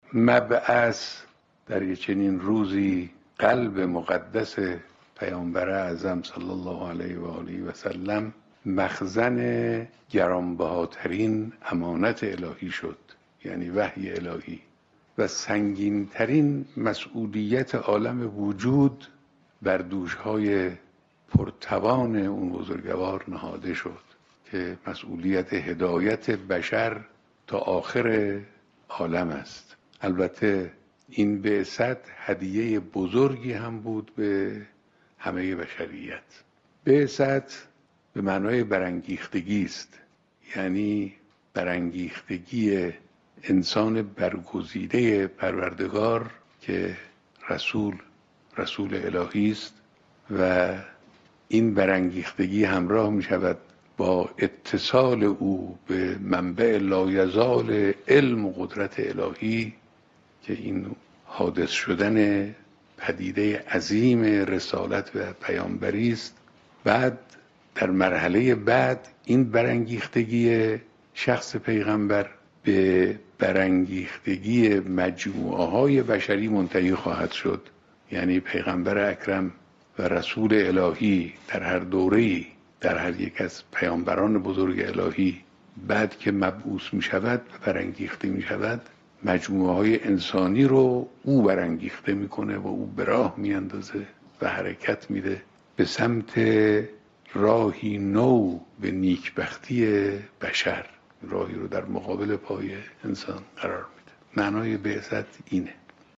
سخنرانی تلویزیونی رهبر انقلاب به مناسبت عیدمبعث
حضرت آیت‌الله خامنه‌ای رهبر انقلاب اسلامی روز (پنج‌شنبه) به مناسبت سالروز عید مبعث پیامبر اعظم صلی‌الله‌علیه‌وآله‌وسلم به‌صورت زنده و مستقیم با ملت شریف ایران سخن گفتند.